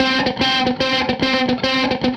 AM_HeroGuitar_110-C01.wav